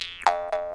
detectorPing2.wav